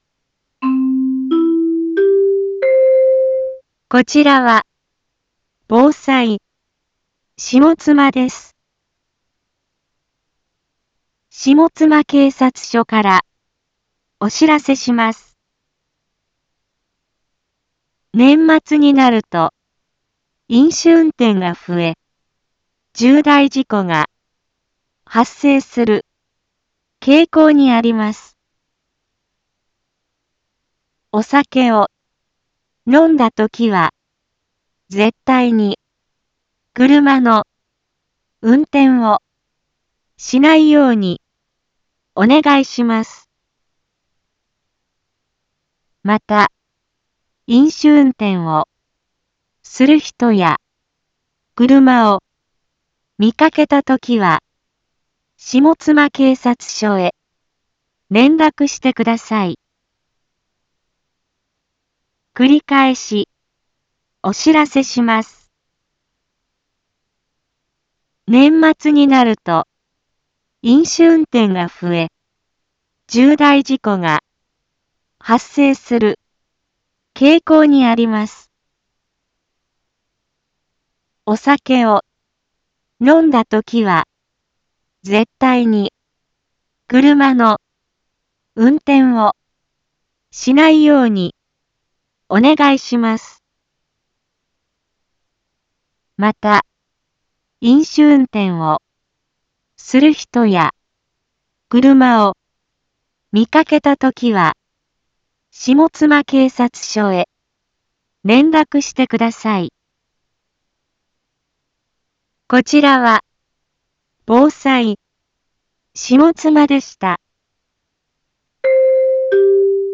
一般放送情報
Back Home 一般放送情報 音声放送 再生 一般放送情報 登録日時：2022-12-02 17:32:05 タイトル：飲酒運転根絶放送 インフォメーション：こちらは、防災、下妻です。